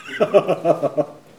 Les sons ont été découpés en morceaux exploitables. 2017-04-10 17:58:57 +02:00 242 KiB Raw Permalink History
rire_03.wav